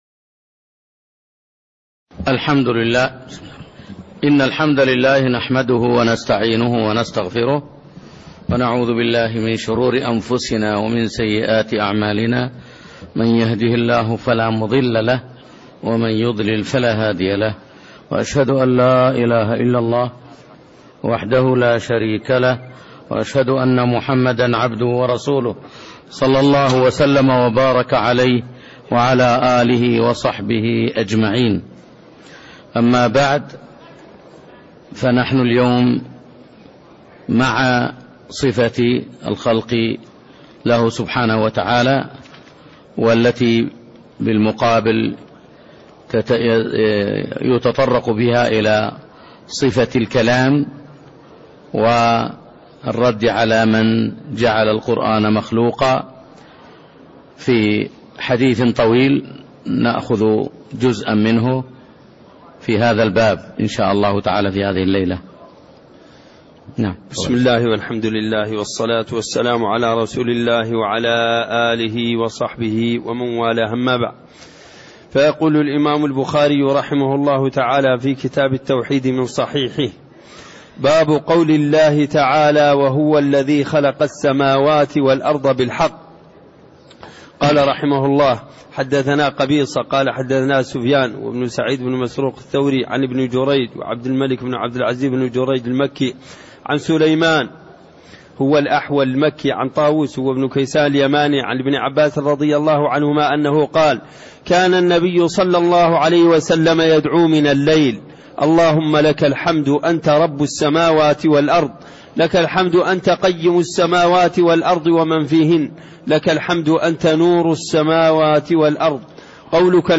تاريخ النشر ٣٠ محرم ١٤٣٣ هـ المكان: المسجد النبوي الشيخ